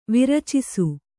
♪ viracisu